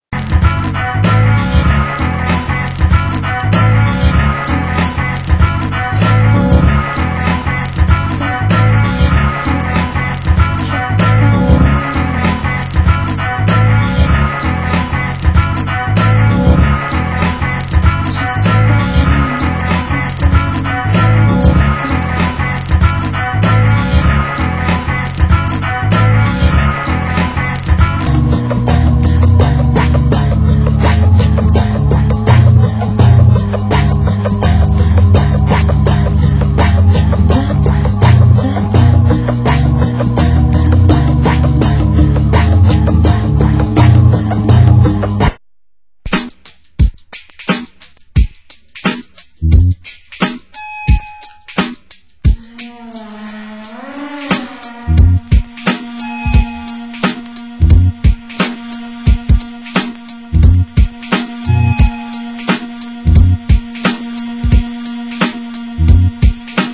Genre: Electronica